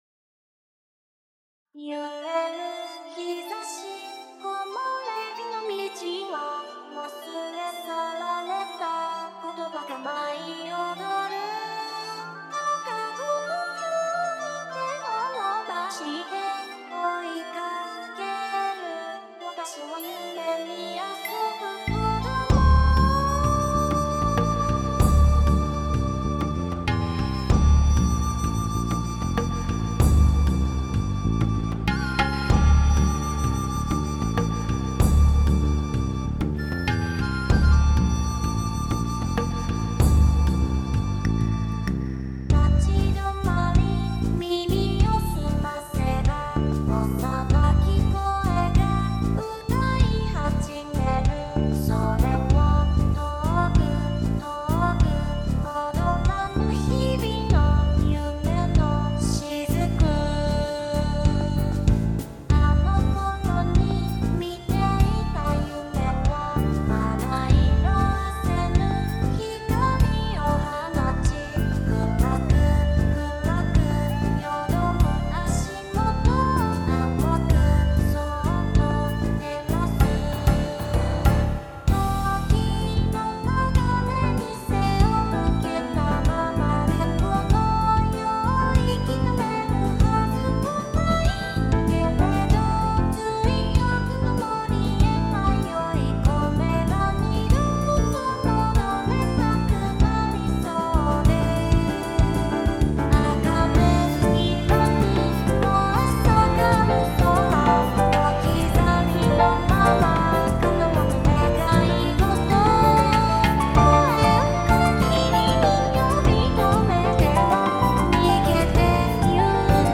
高校の頃に作り始めてずっと未完成だった民族系オリジナル曲です。ひたすら、ひたすら、好きな音を詰め込みました。
UTAU